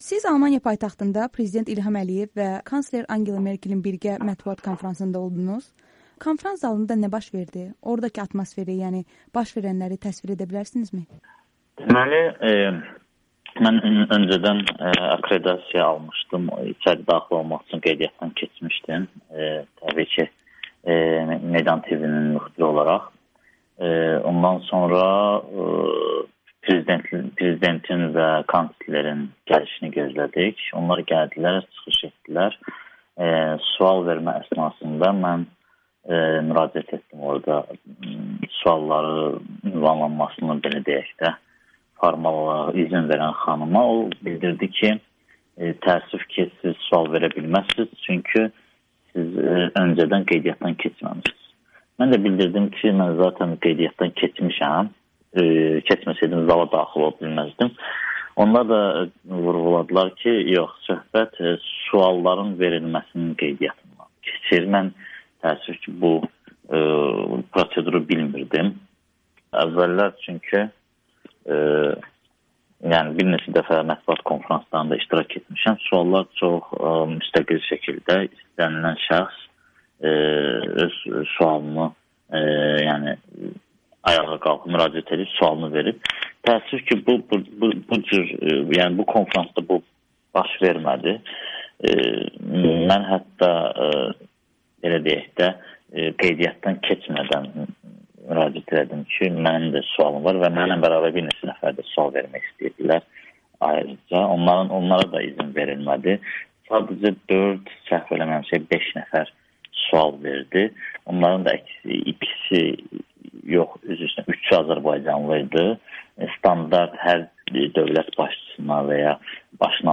Onunla müsahibəni təqdim edirik: